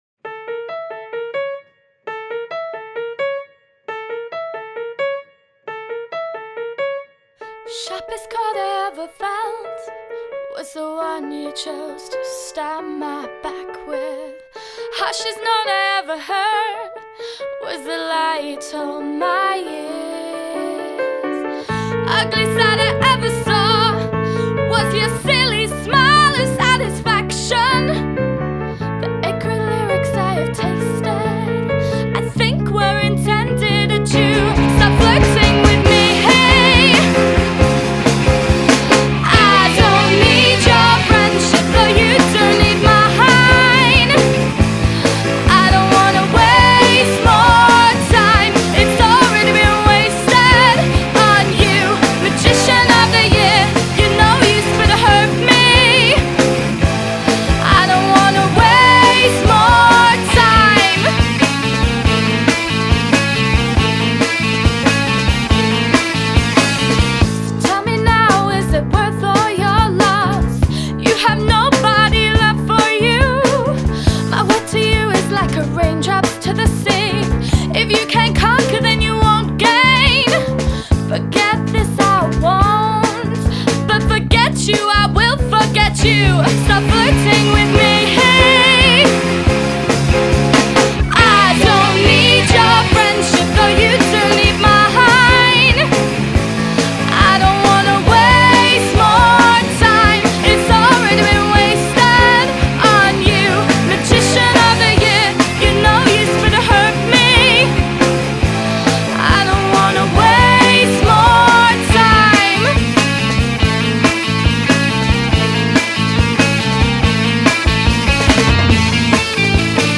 Industrial
Punk
Experimental